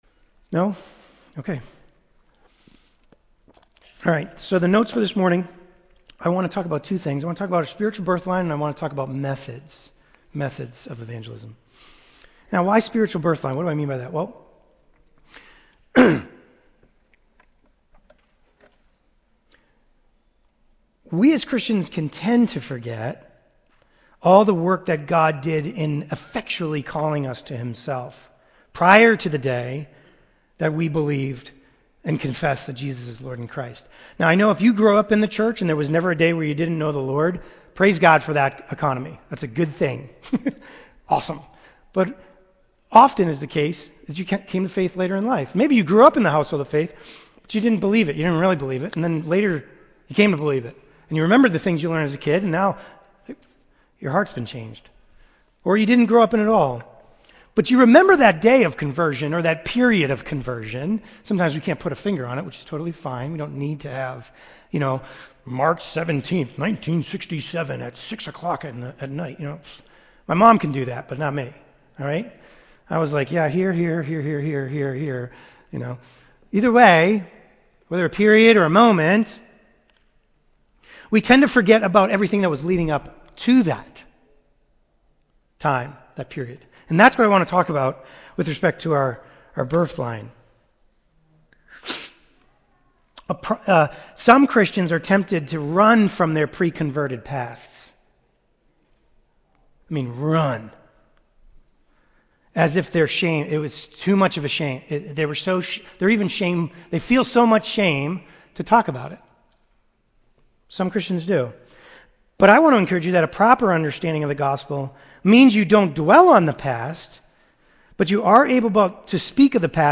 Sunday School Classes